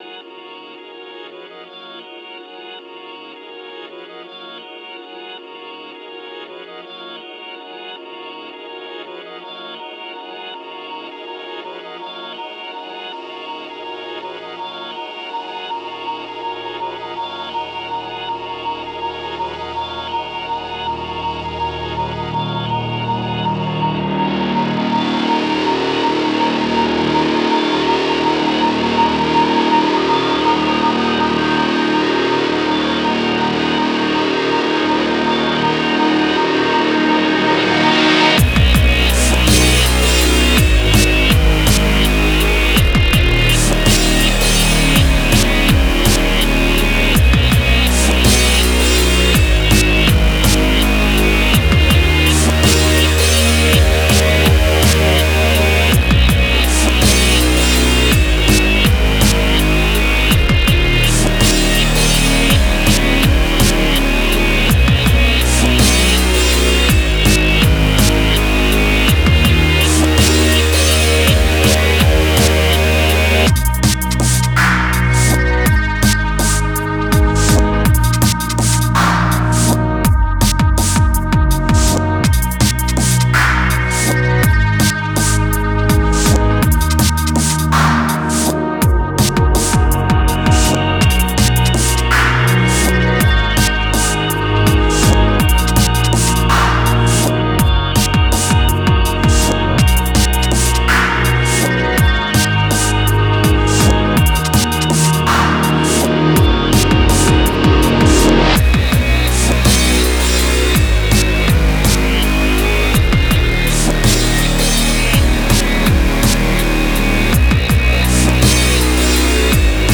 Remix I did of my friends post rock band